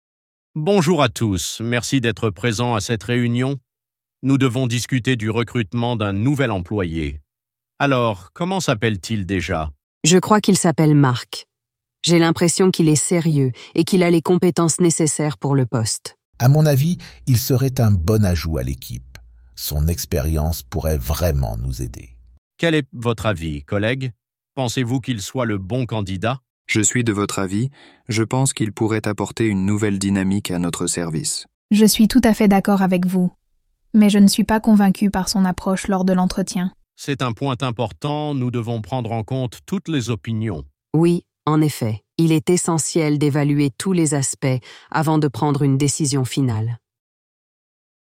Dialogue – Réunion de recrutement